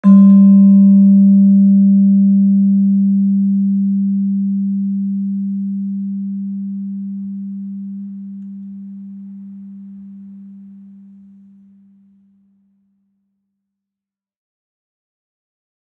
Gamelan / Gender
Gender-2-G2-f.wav